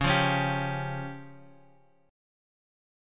C#Mb5 chord {x 4 3 x 2 3} chord
Csharp-Major Flat 5th-Csharp-x,4,3,x,2,3.m4a